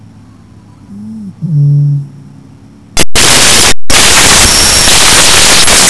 Rohrdommel (Botaurus stellaris)
Stimme: klingt wie ein Nebelhorn, dumpfes »ü-wump«, im Flug »ahrk«
Botaurus.stellaris.wav